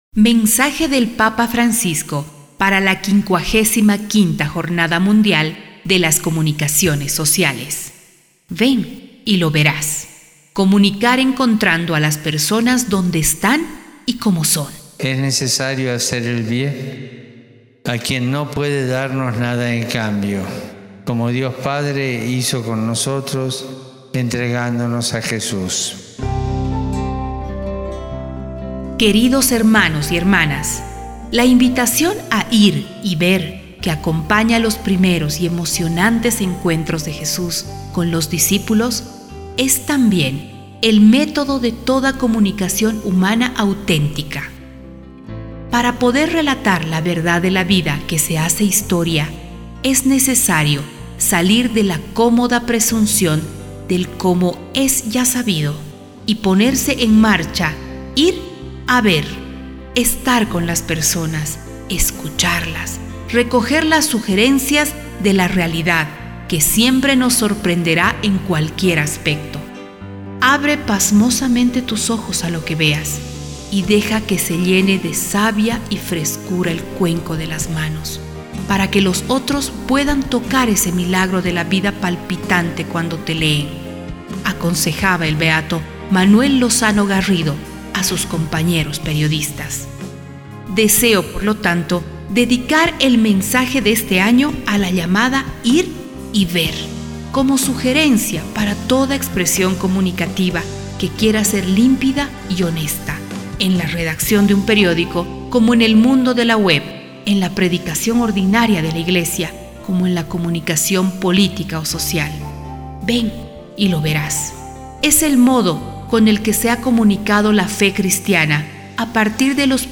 Acto Académico Presentación del mensaje del Papa Francisco a la LV Jornada Mundial de las Comunicaciones Sociales